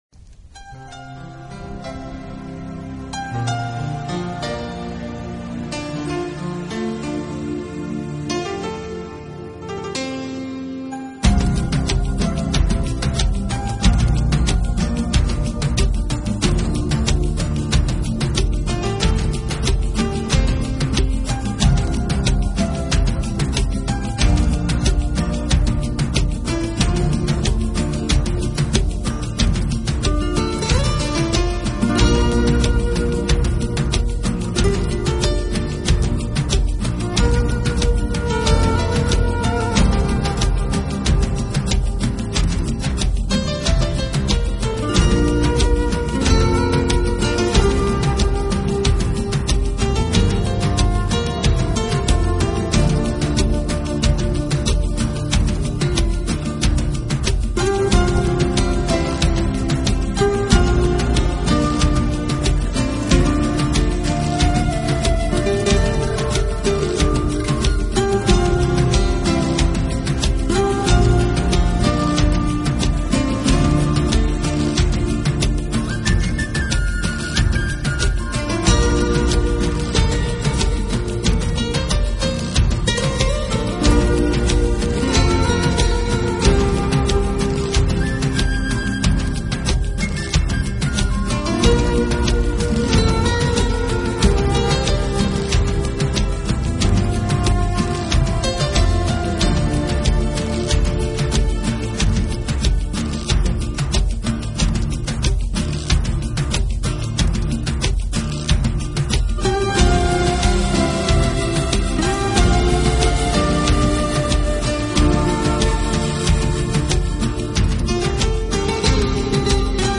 新世纪长笛
音乐类型：NEW AGE